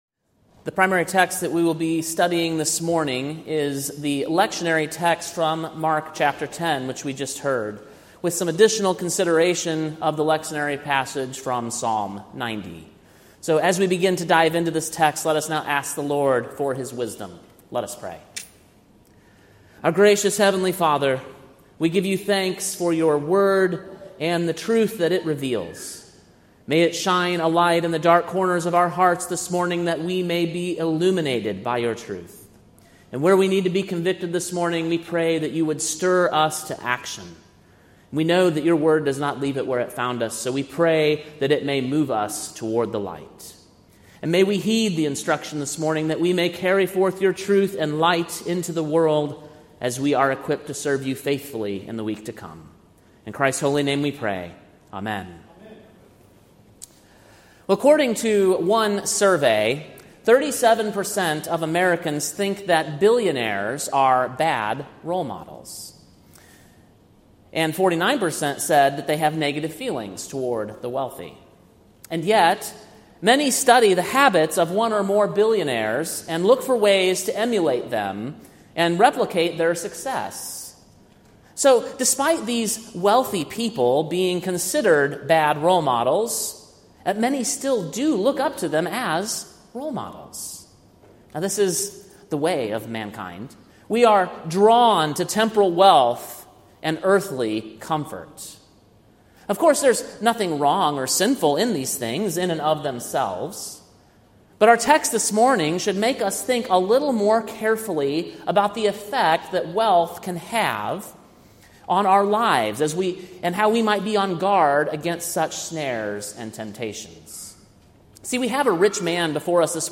Sermon preached on October 13, 2024, at Trinity Reformed Church Church, Chattanooga, TN.